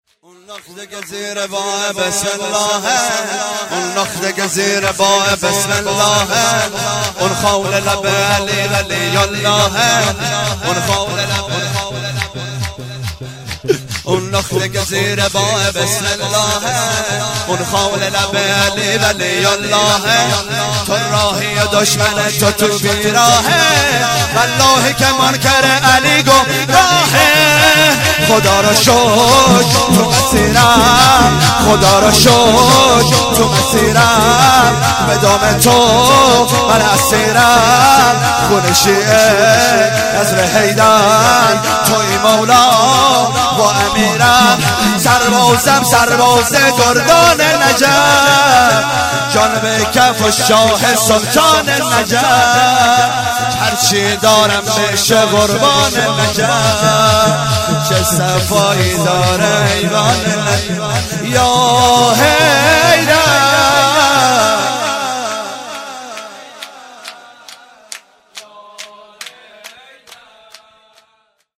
شور - اون نقطه که زیر با بسم اللهِ
جشن مبعث - جمعه24 فروردین 1397